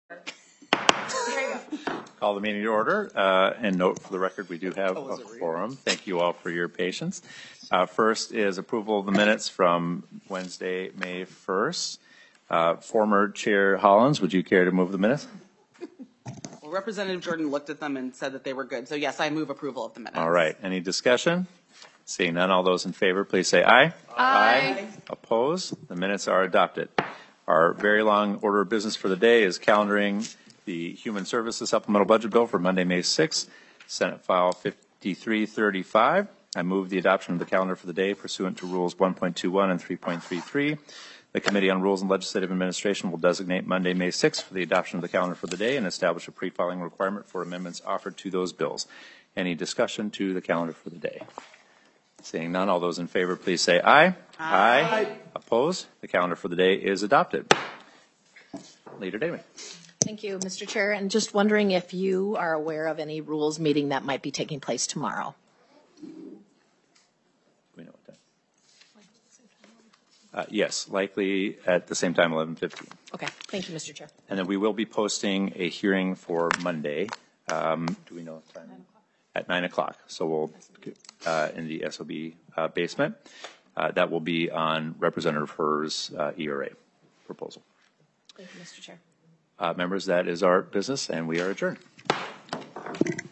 Rules and Legislative Administration Committee Minutes
Representative Jamie Long, Chair of the Committee on Rules and Legislative Administration, called the meeting to order at 11:28 am on May 2, 2024, in Capitol 120.